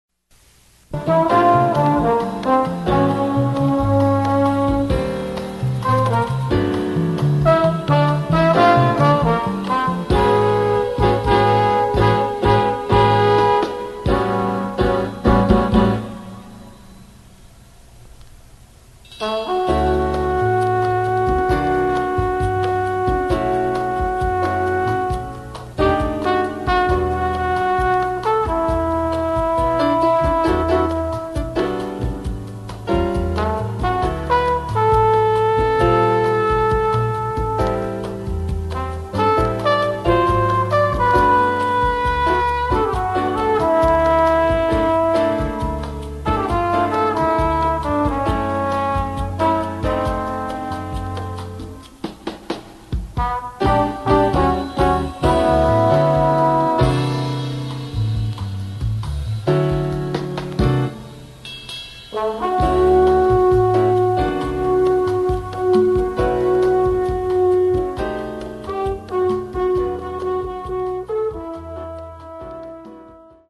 Category: little big band Style: bossa